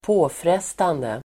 Uttal: [²p'å:fres:tande]